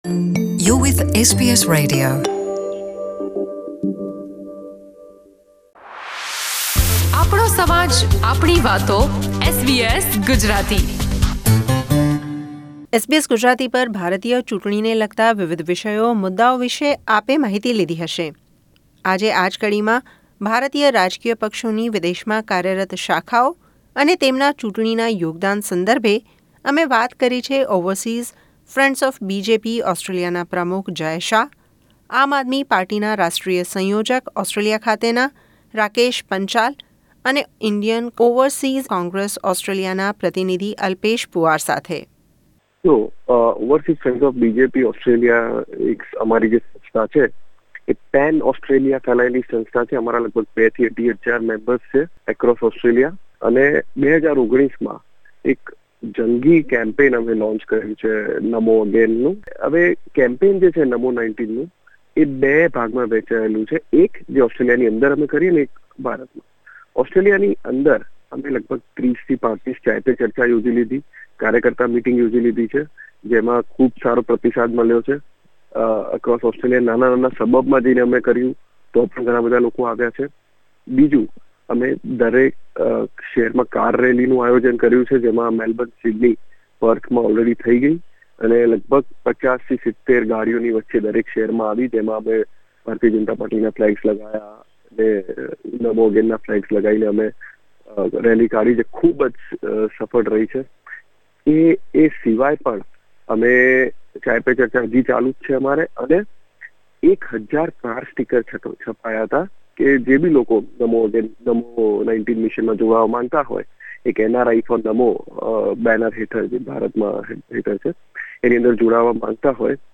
મોટાભાગના ભારતીય રાજકીય પક્ષો તેમની શાખા વિદેશમાં ધરાવે છે, આ કદાચ જે- તે પક્ષની વિદેશી કૂટનીતિનો ભાગ હોઈ શકે. ઓસ્ટ્રેલિયામાં કાર્યરત ભાજપ, આપ અને કોંગ્રસ પક્ષના પ્રતિનિધિઓ સાથે લોકસભાની ચૂંટણી નિમિત્તે થયેલ વાતચીત દરમિયાન જાણ્યું તેમના રાજકારણનાયોગદાન વિષે અને NRI સમુદાય માટે ચૂંટણી ઢંઢેરા માં કરાયેલ જોગવાઈઓ વિષે.